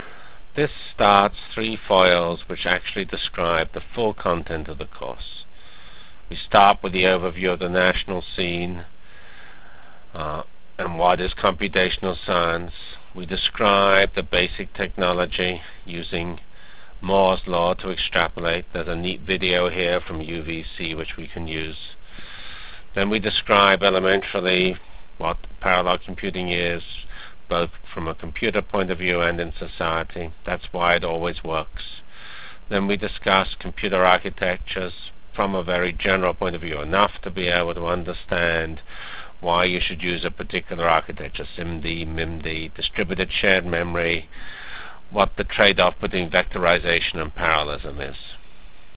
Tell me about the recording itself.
From Remarks on Parallel Computing and HPCC Education Ohio Supercomputer Center Workshop -- January 24 1997.